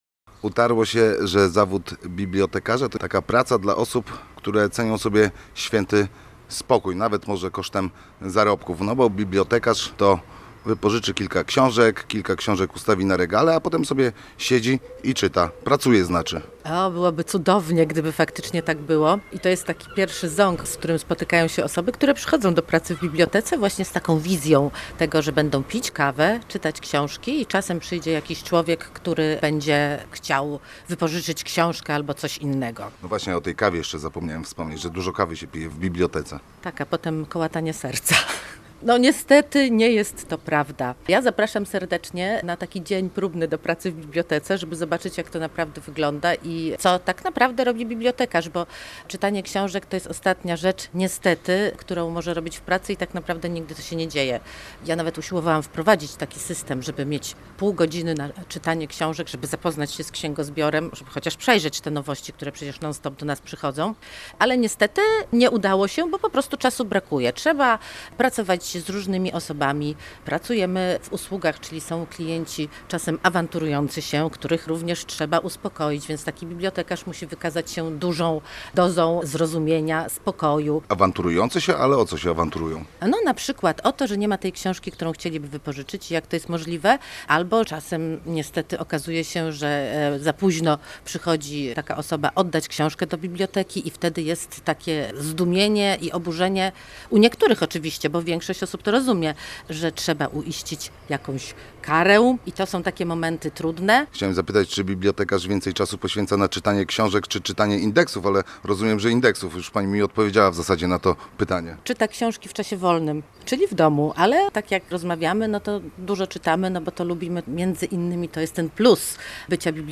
Więcej o pracy bibliotekarzy i o tym, dlaczego w pewnym momencie mieszkańcy Gdyni zaczęli zasypywać biblioteki starymi ubraniami w materiale naszego reportera: https